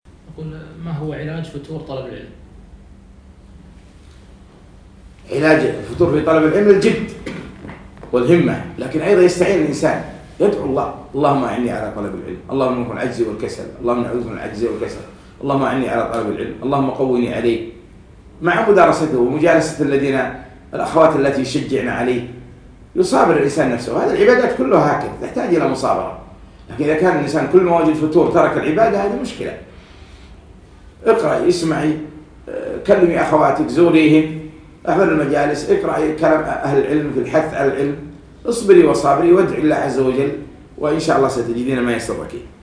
مقتطف من محاضرة فوائد وعبر من سورة الكهف المقامة في مركز إلهام البوشي بتاريخ 3 4 2017